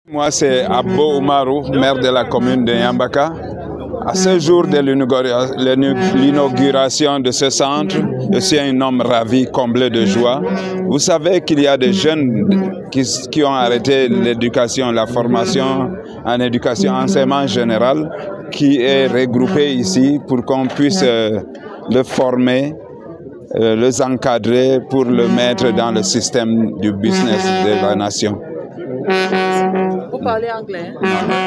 Le mot du maire